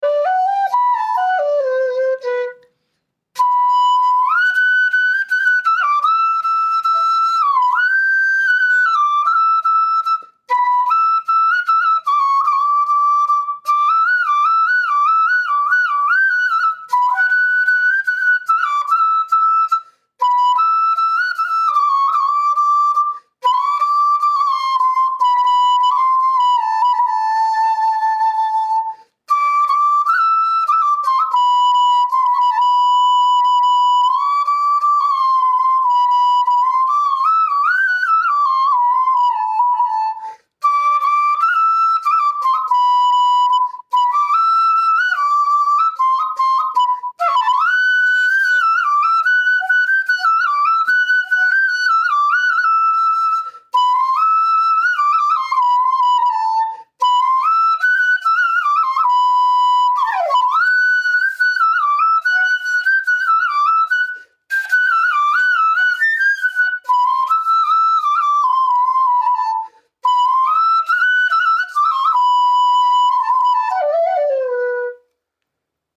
flutist